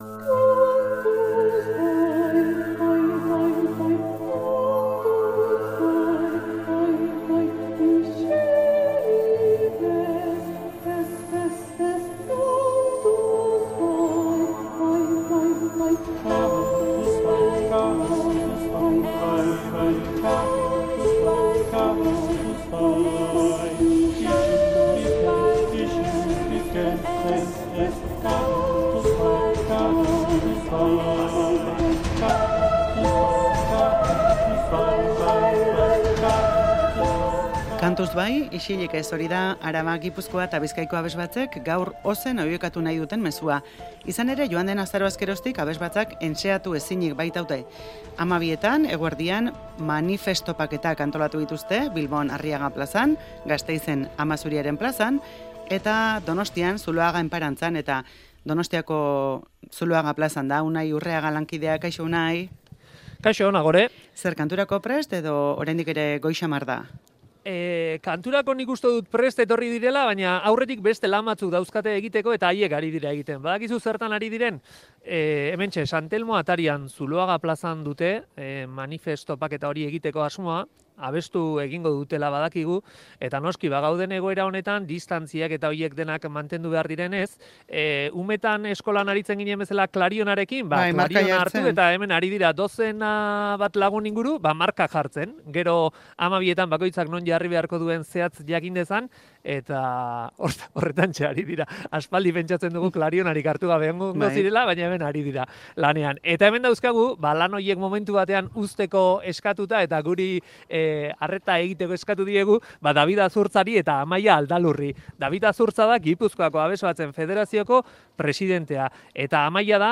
Audioa: Araba, Bizkaia eta Gipuzkoako abesbatzek entseatzeko baimena eskatu dute, azarotik elkartu ezinik baitaude. Kalera atera dira, kantuan.